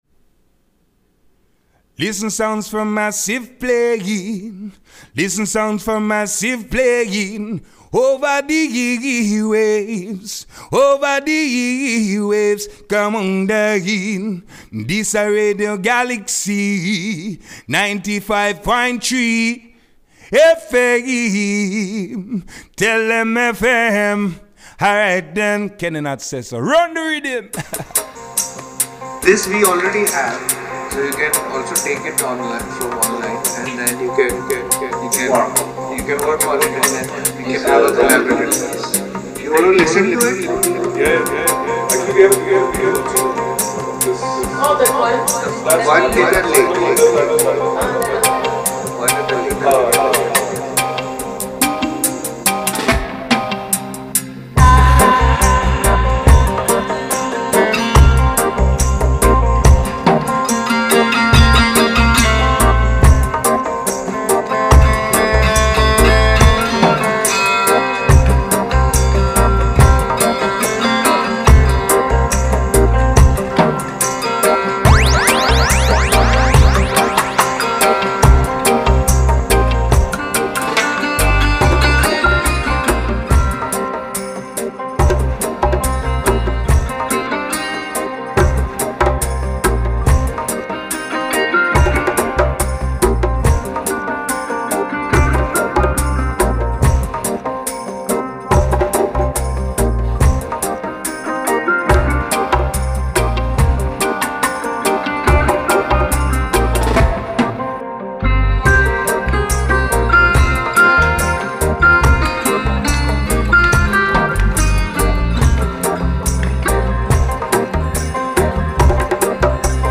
reggaephonique